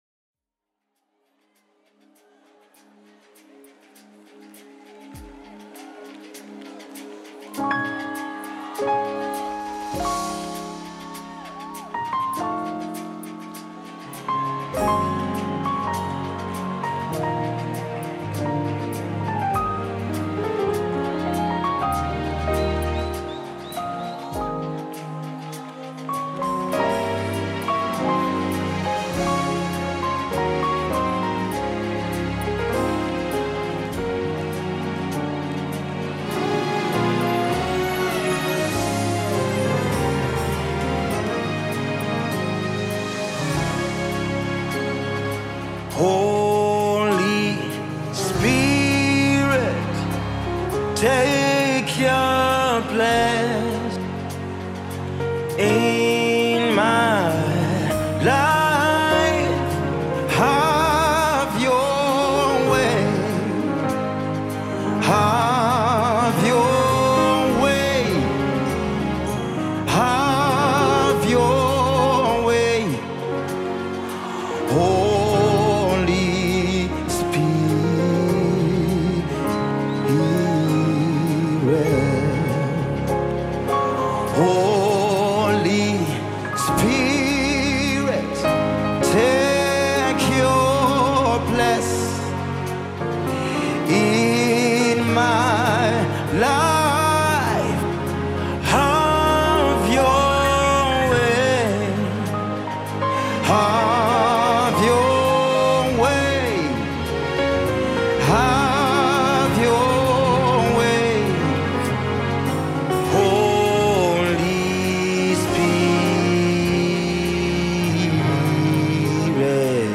a gifted Kenyan gospel artist